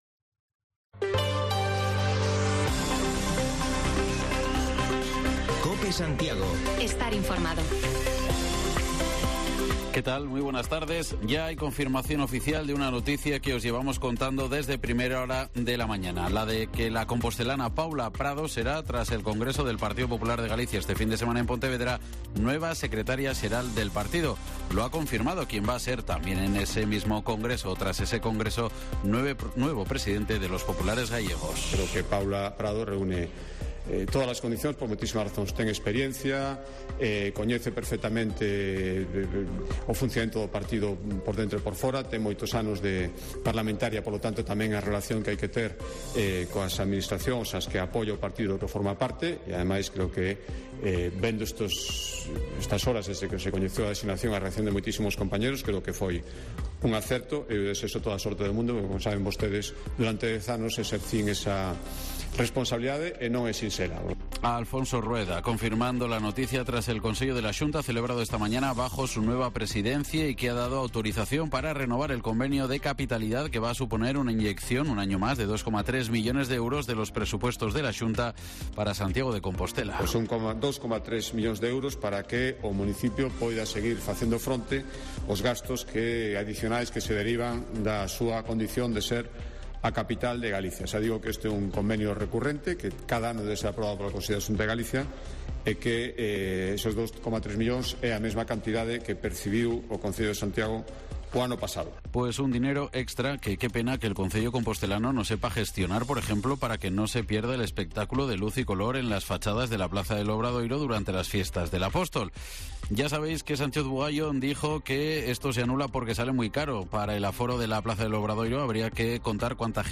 Resumen de los asuntos fundamentales de este jueves en Santiago y Barbanza: el nombre propio es el de Paula Prado, la compostelana que se convertirá este fin de semana en la número dos del PpdG. Hablamos también del convenio de Cáritas y Gaes para atender a personas con problemas de audición que cuentan con pocos recursos y bajamos los micrófonos de Cope a la calle para saber qué piensa la gente de Santiago sobre la supresión del espectáculo de luz y sonido en el Obradoiro...¿y si se mantuviese con entrada de pago?